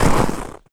STEPS Snow, Run 18.wav